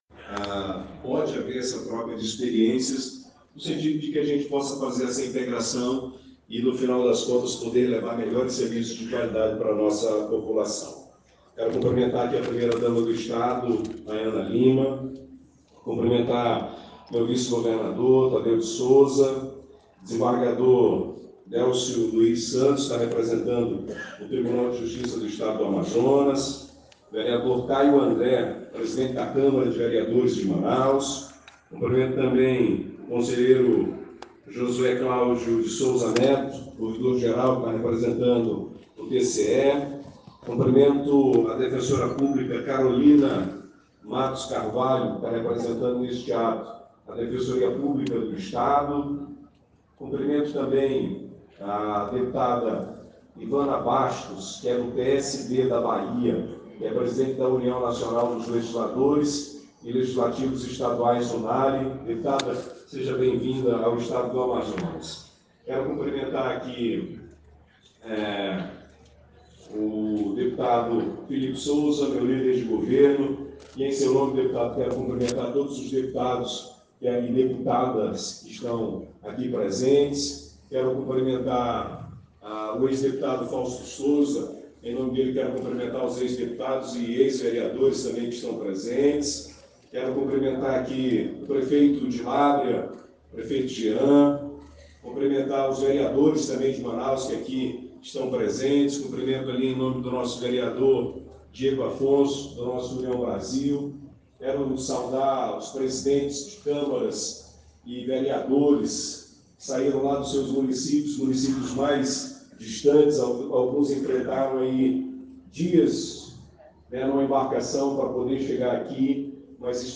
A solenidade de abertura contou com a presença do presidente da Aleam, Roberto Cidade (União Brasil), do governador do Amazonas, Wilson Lima (União Brasil), do vice-governador Tadeu de Souza (Avante), de  autoridades políticas e  judiciárias do Estado.
Discurso do governador Wilson Lima onde fala sobre a importância do apoio dos deputados estaduais para o Amazonas